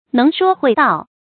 注音：ㄣㄥˊ ㄕㄨㄛ ㄏㄨㄟˋ ㄉㄠˋ
能說會道的讀法